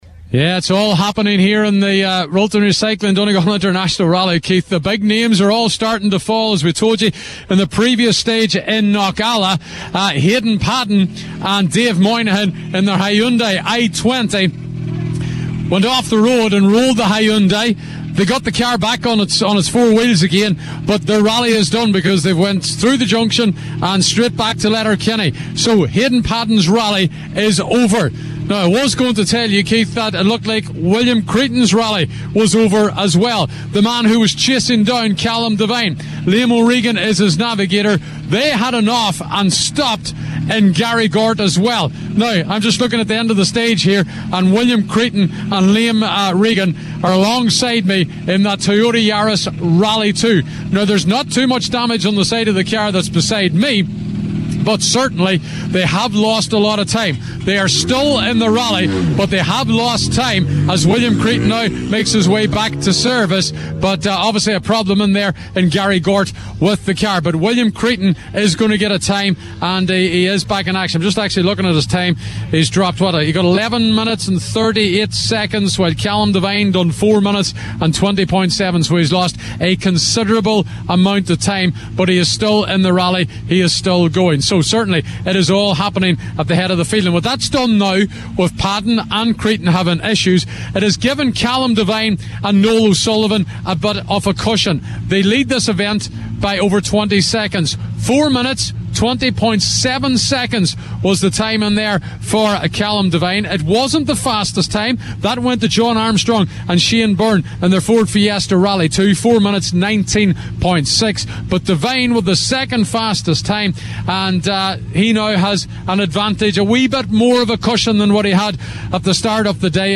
reported live after the final stage of the day